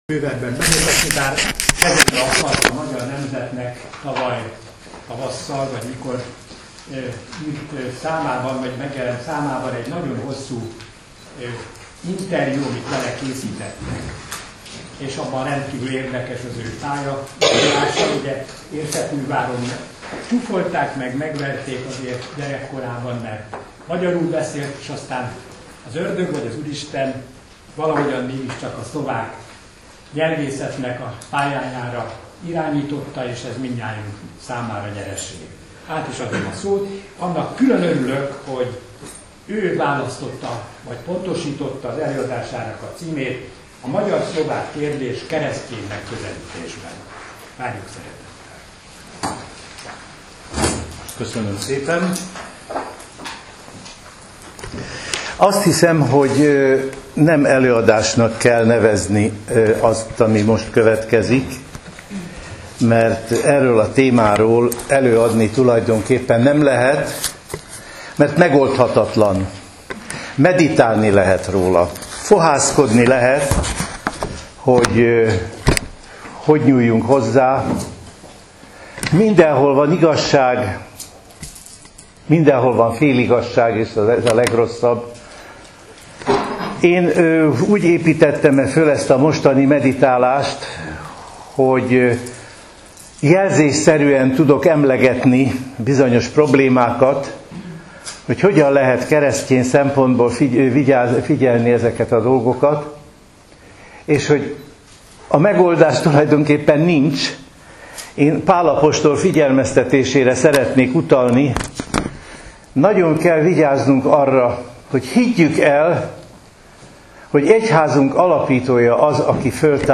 Az előadások hanganyaga: A magyar-szlovák kérdés keresztyén megközelítésben